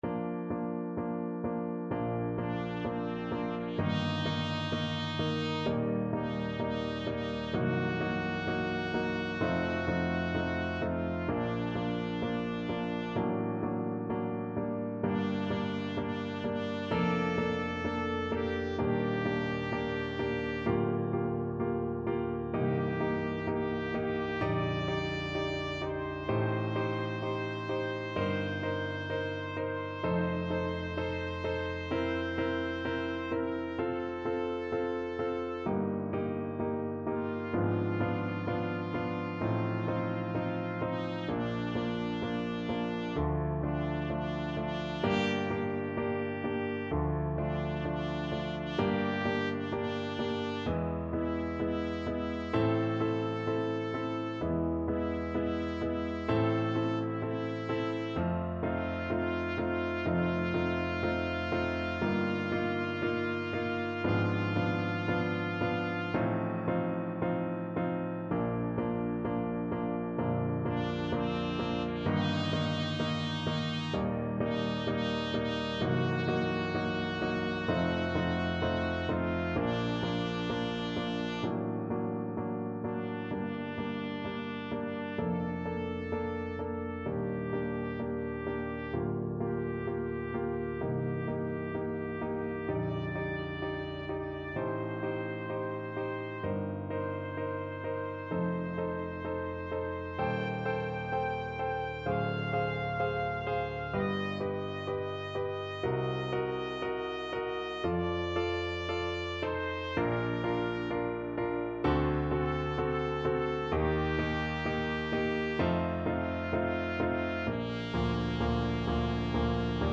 Nicht zu schnell = 64 Nicht zu schnell